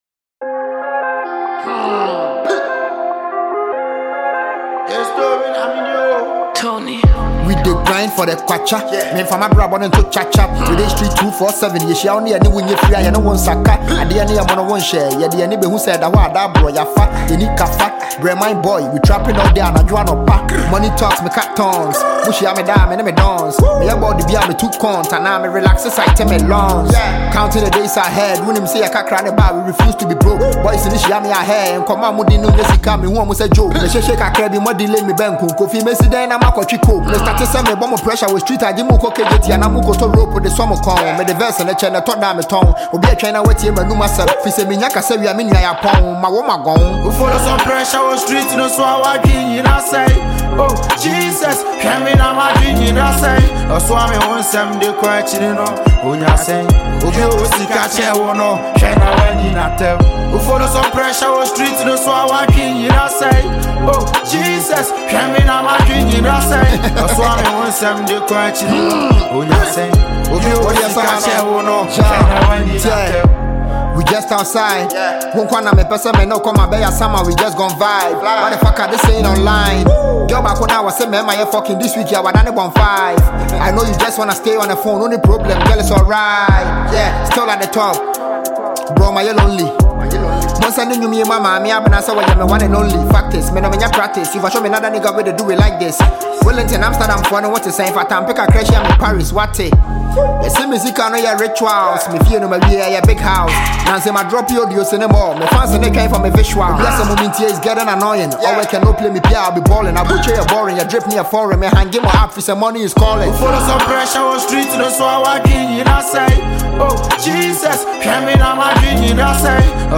Ghana Music
high-energy rap sound
captivating vocals
With its infectious beat and universal lyrics